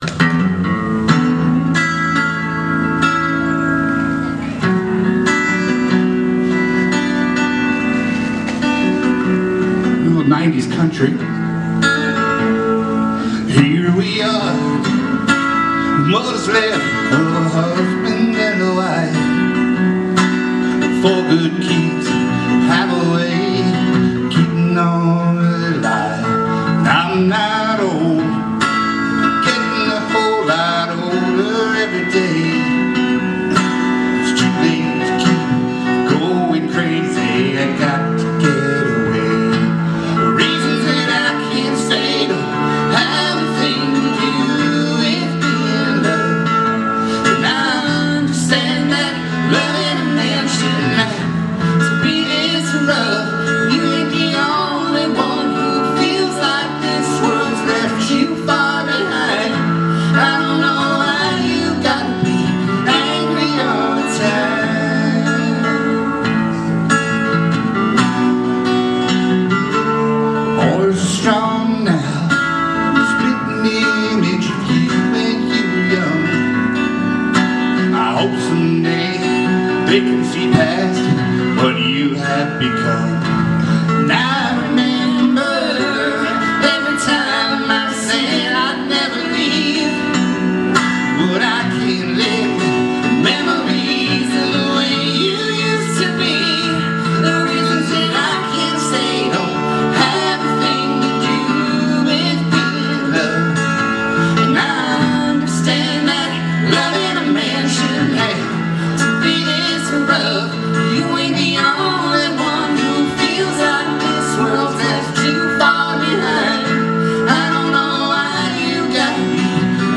Live Anderson River Park 10/25